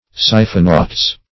Search Result for " cyphonautes" : The Collaborative International Dictionary of English v.0.48: Cyphonautes \Cyph`o*nau"tes\ (s?f`?-n?"t?z), n. [NL., fr. Gr.